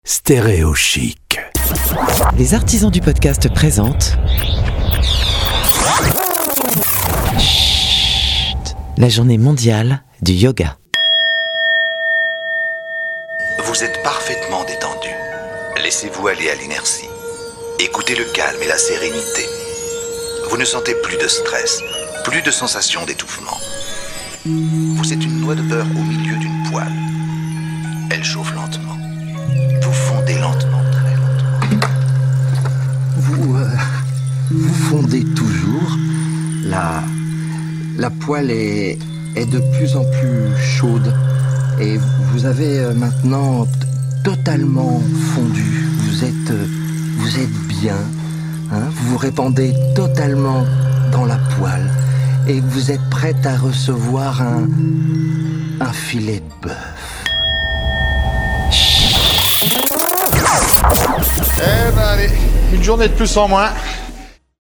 A l'occasion de la Journée Mondiale du Yoga, le 21 Juin 2021, voici une immersion de 60 secondes avec Schhhhhht produit par les Artisans du Podcast.